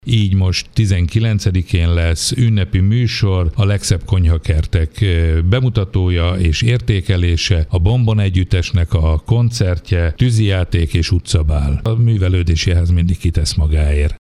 Nagy András Gábor polgármestert hallják.